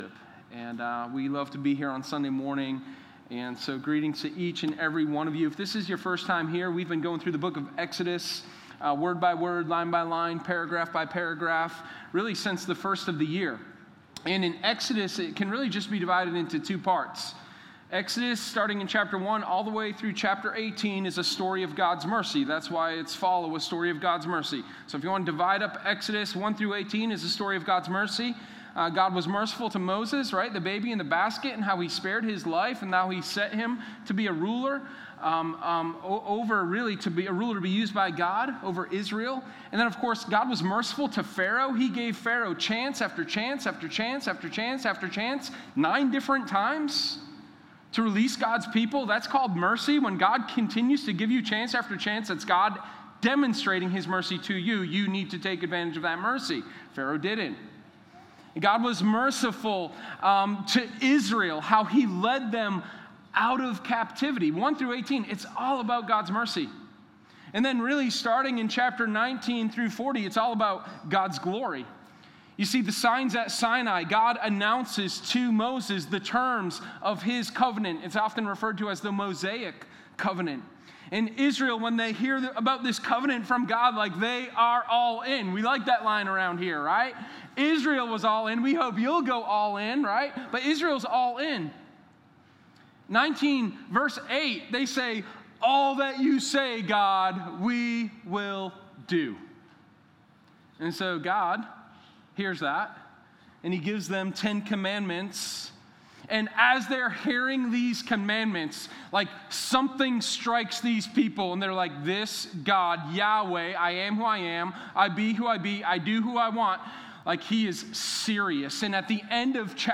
Sermon1021_TheLawMatterspt.2.mp3